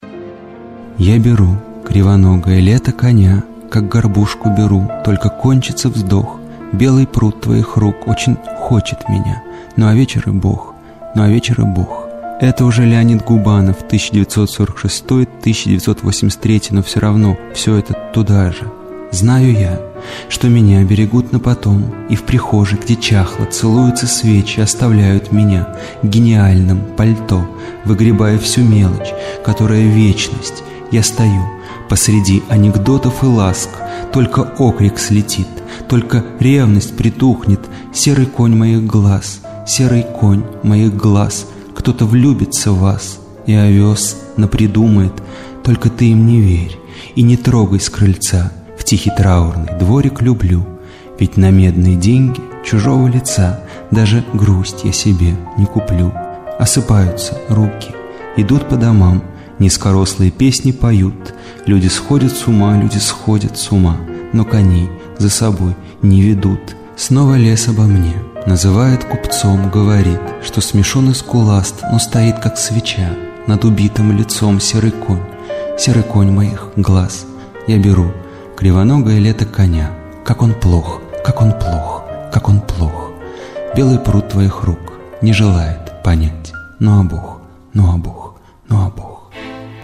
читает Дмитрий Воденников